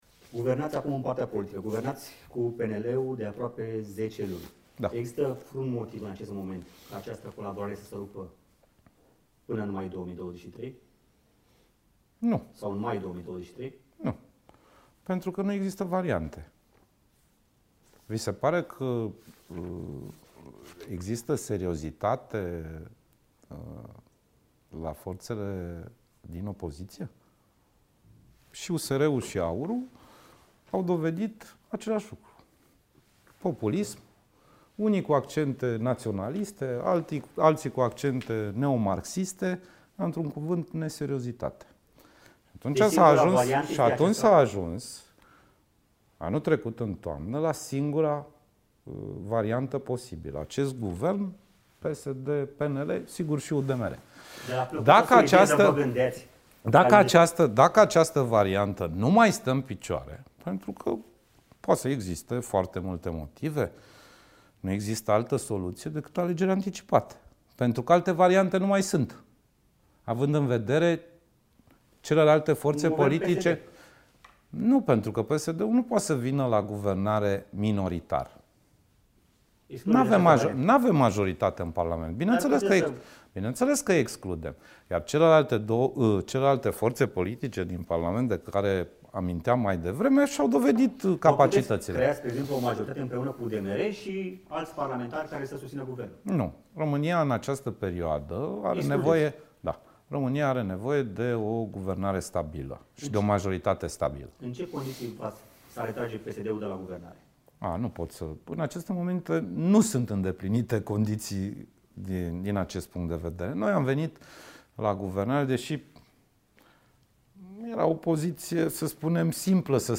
Vicepremierul Sorin Grindeanu, prim-vicepreședinte PSD, subliniază într-un interviu pentru Europa Liberă că numărul doi din NATO, Mircea Geoană, trebuie să se înscrie în PSD pentru a fi un candidat redutabil la prezidențiale și pentru a avea sprijinul social-democraților.